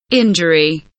injury kelimesinin anlamı, resimli anlatımı ve sesli okunuşu